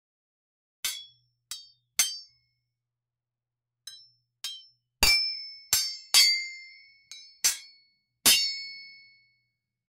Sound effect of sword clash with lighting
sound-effect-of-sword-cla-nr2io6e7.wav